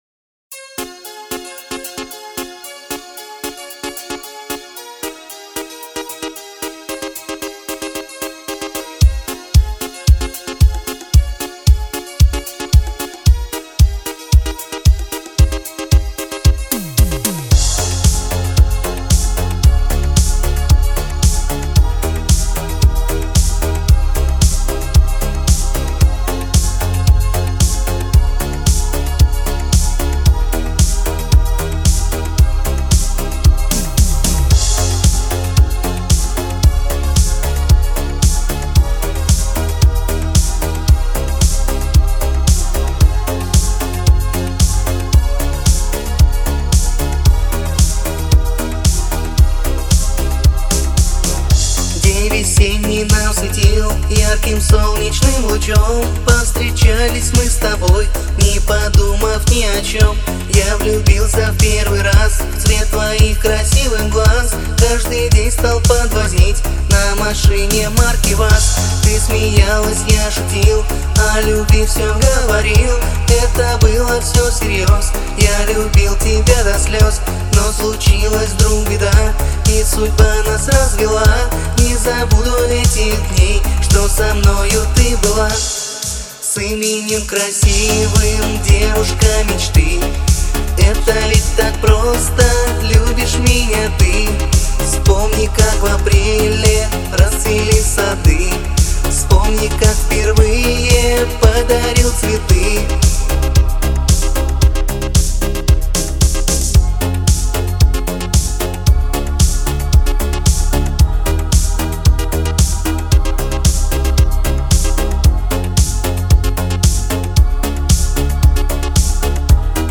романтическая поп-песня